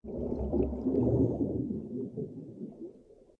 SZ_DD_Underwater.ogg